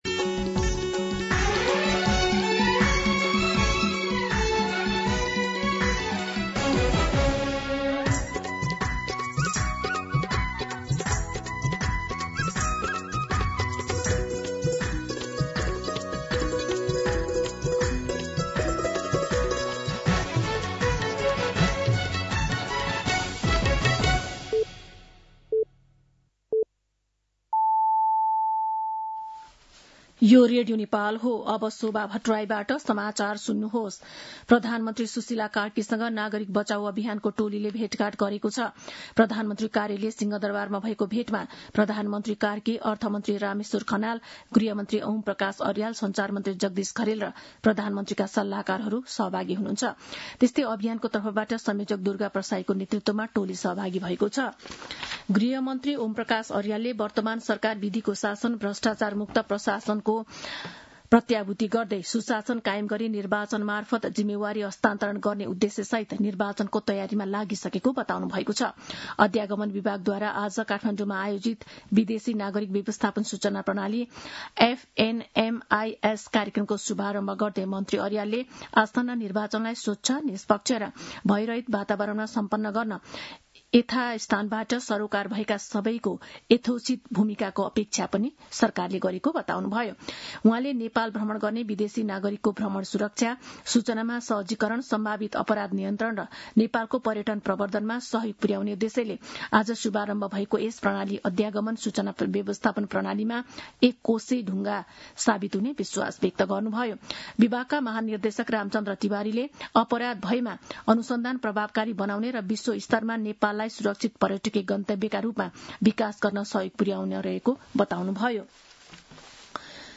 दिउँसो ४ बजेको नेपाली समाचार : १७ पुष , २०८२